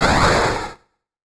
redpandaexplodes3.wav